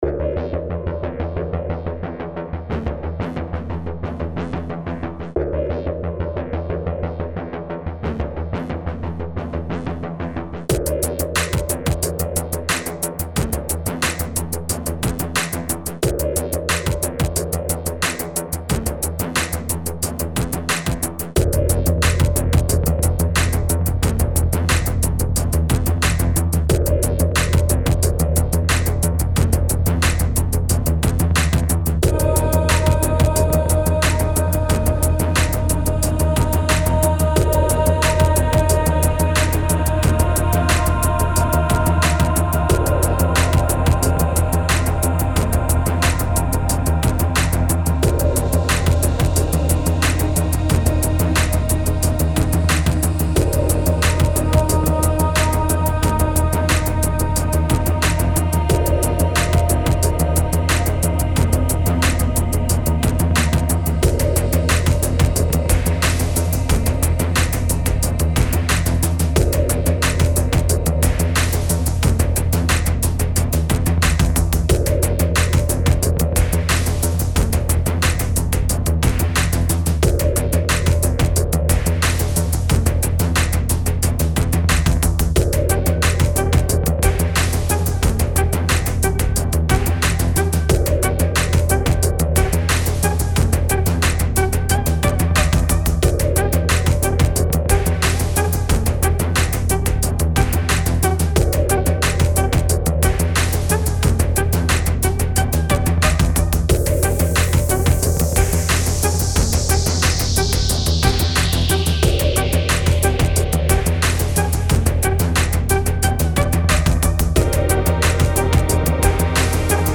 Genre Electronica